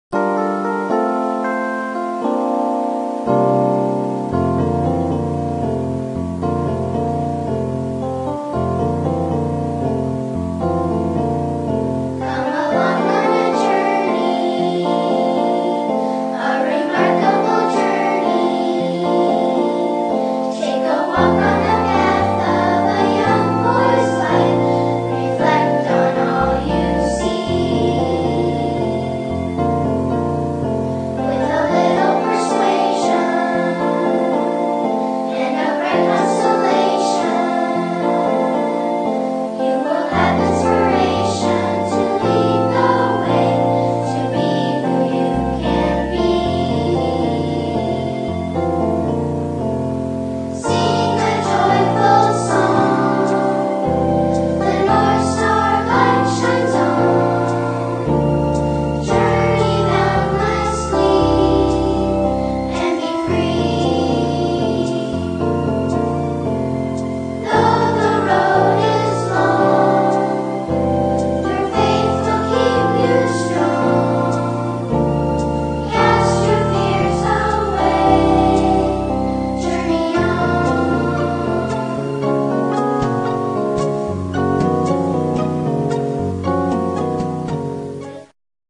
performed as instrumentals by the composer himself.